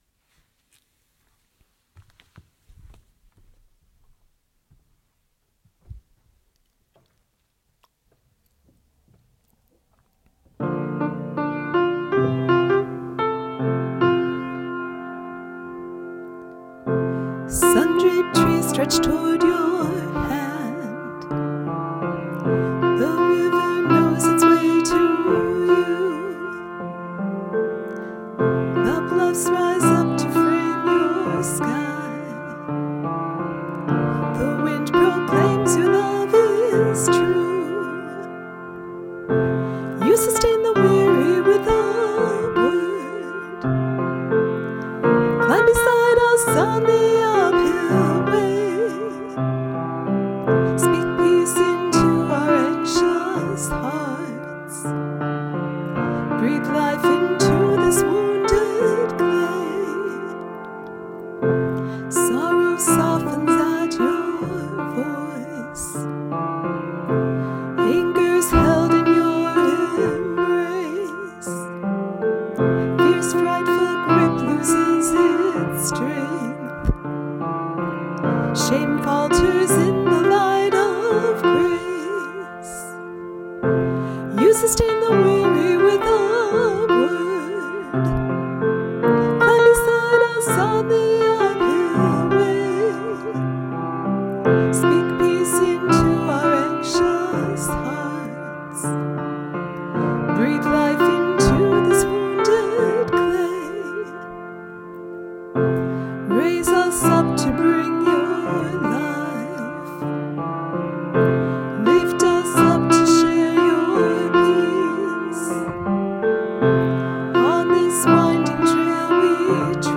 I didn’t trust myself to play the piano for you today, so I recorded the song in advance.